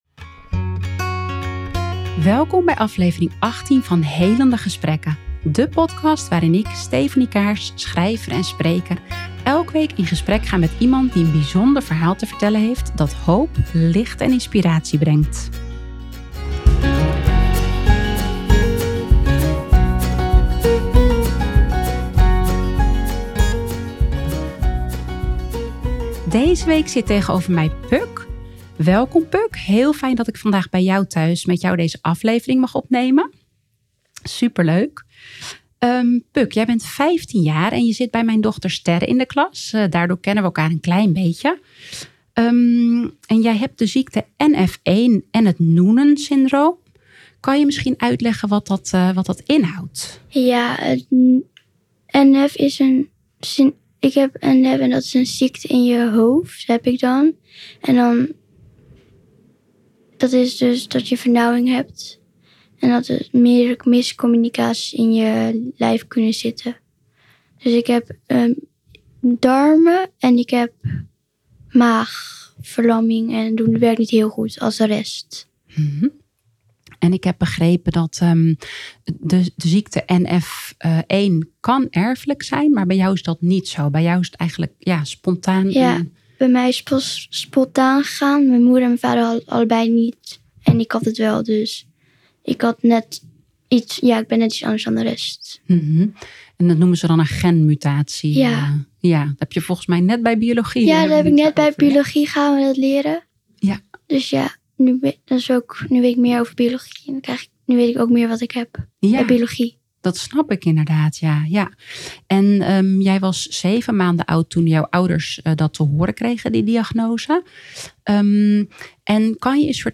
waarin ik om de week in gesprek ga met iemand die een bijzonder verhaal te vertellen heeft dat hoop, licht en inspiratie brengt.